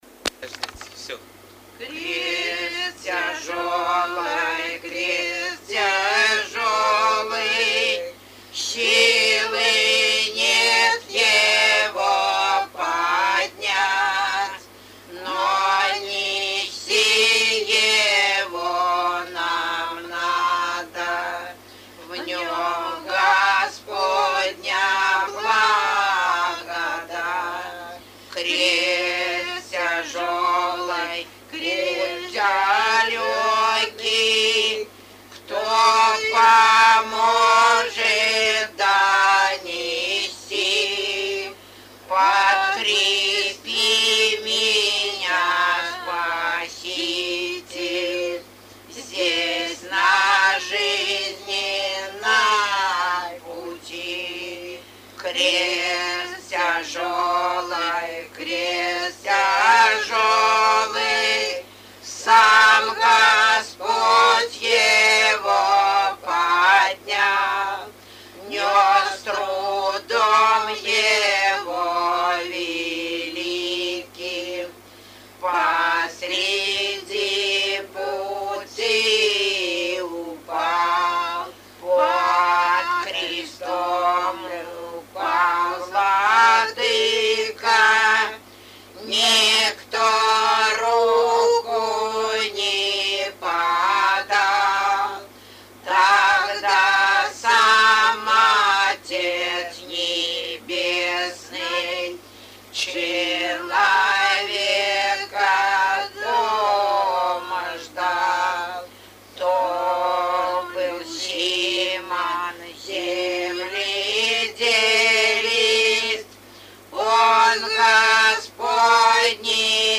01 Духовный стих «Крест тяжелый, крест тяжелый» в исполнении жительниц с. Жемковка Сызранского р-на Самарской обл.